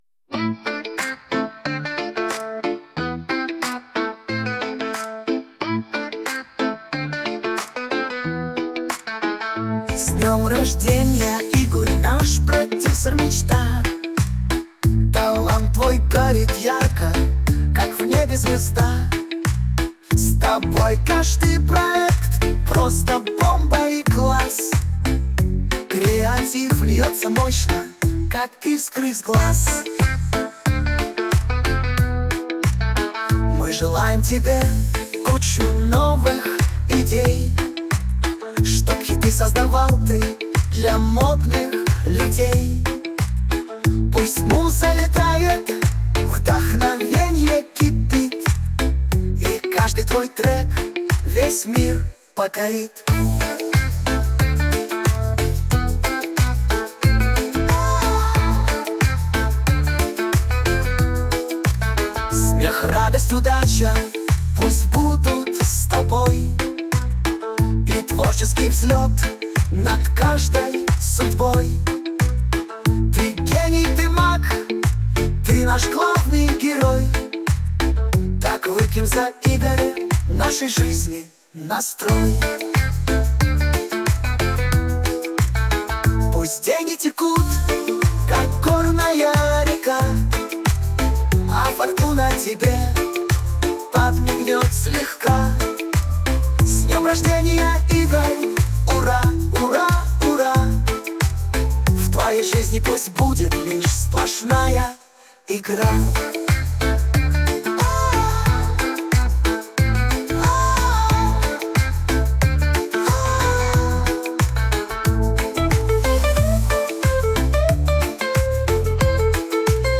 Я написала песню в подарок.Зацените Песня в стиле регги, для хорошего настроения.